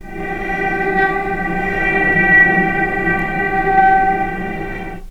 vc_sp-G4-pp.AIF